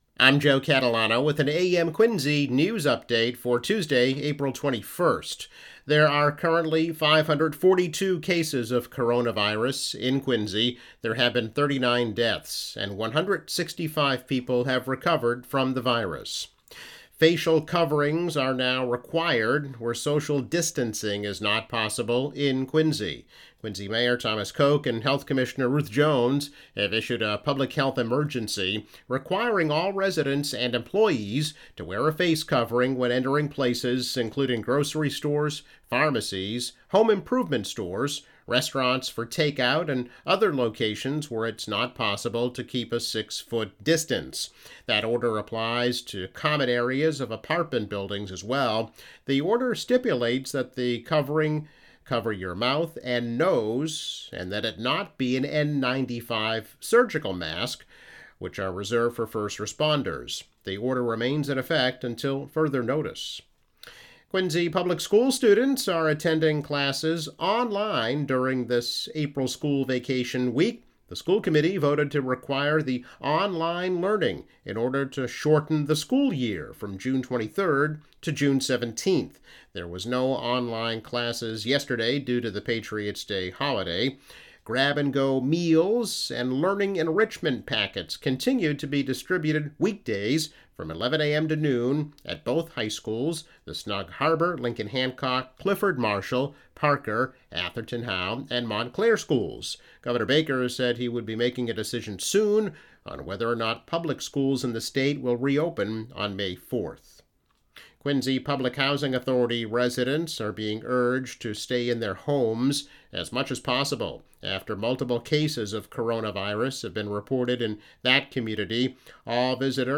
Daily news update.